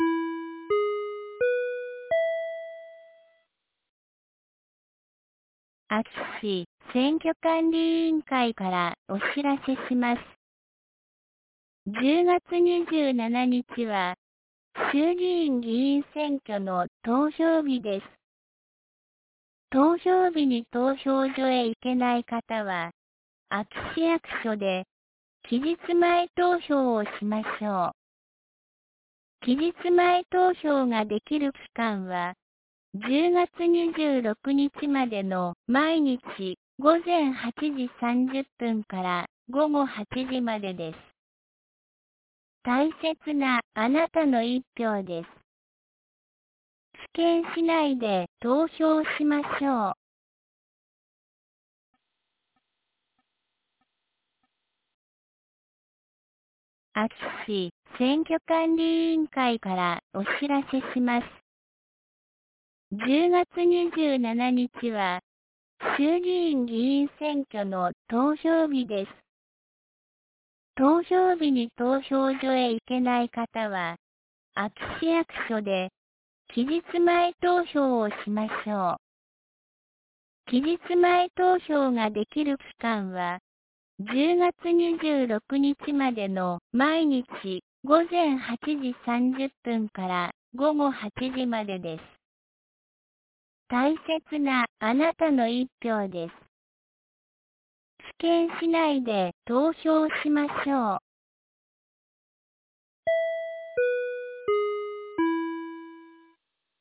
2024年10月24日 10時01分に、安芸市より井ノ口へ放送がありました。